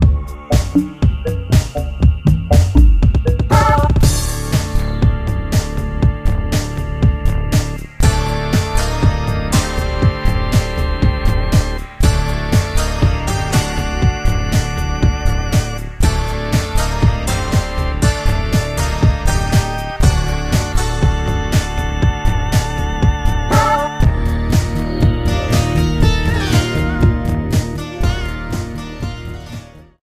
Directly ripped from the ISO
Faded in the end